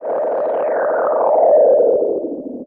Filtered Feedback 04.wav